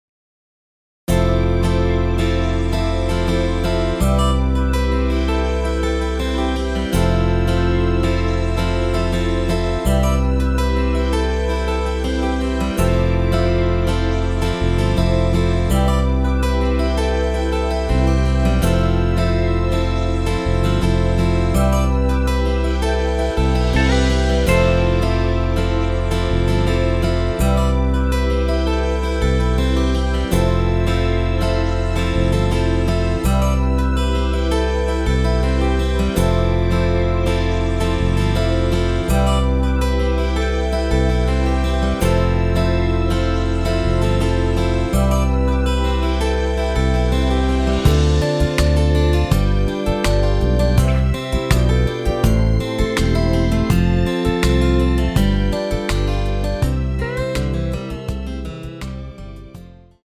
엔딩이 페이드 아웃이라 라이브 하시기 좋게 엔딩을 만들어 놓았습니다.
원키에서(+5)올린 MR입니다.
여성분이 부르실수 있는 키로 제작한 MR 입니다.(미리듣기 참조)
앞부분30초, 뒷부분30초씩 편집해서 올려 드리고 있습니다.